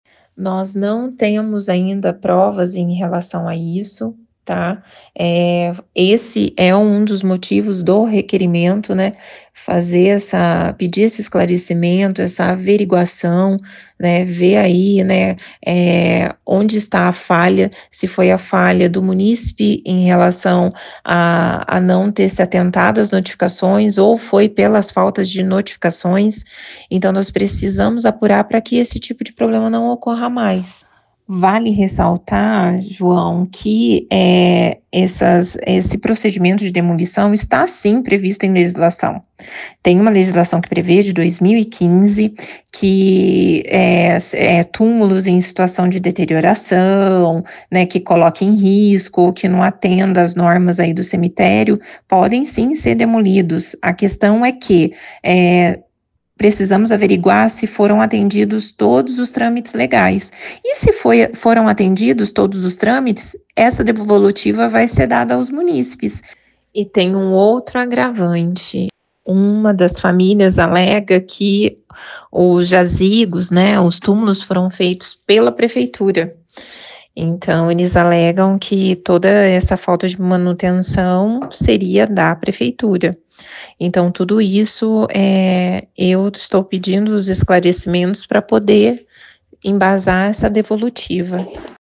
A vereadora Professora Hellen (PODE) concedeu uma entrevista exclusiva à 92FM São João, na qual abordou a polêmica sobre a suposta venda irregular de túmulos no Cemitério de São João da Boa Vista.